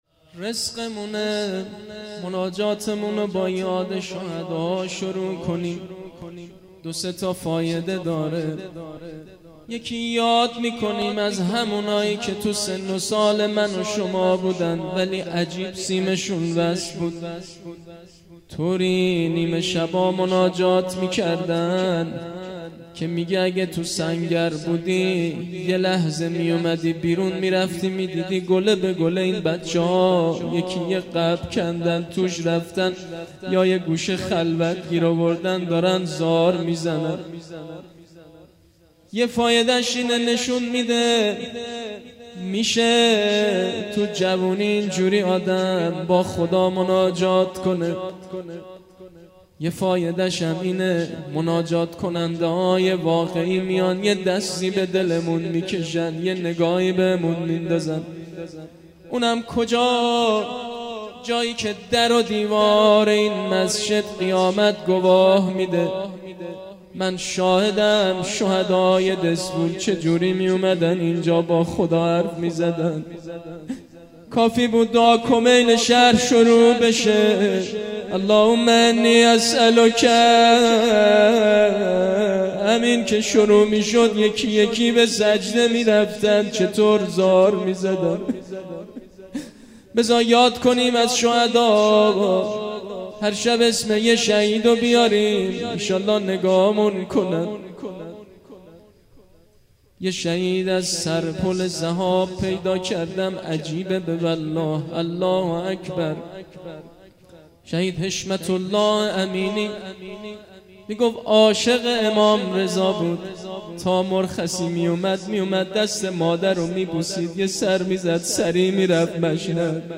فایل های صوتی شب دوم از سلسله مراسم وعظ و شب زنده داری